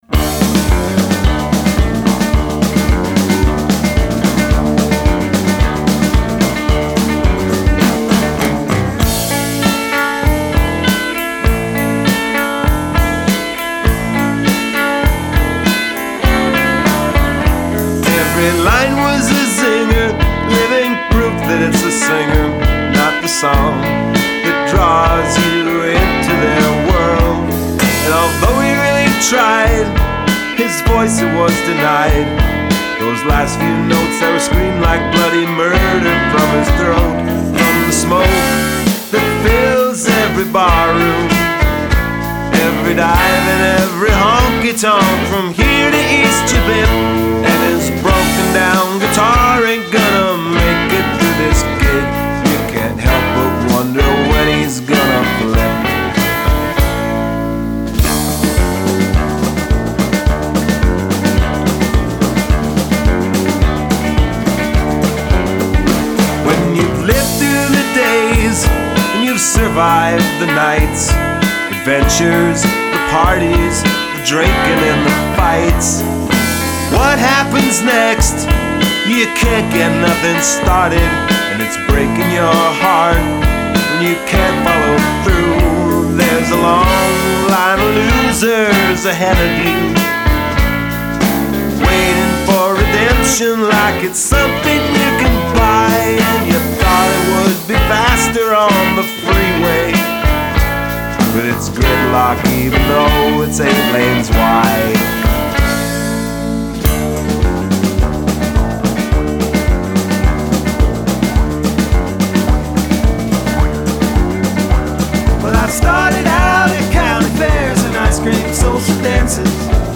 drums & percussion
bass, keyboards and vocals
Recorded at Flowerpot Studio, Minneapolis MN.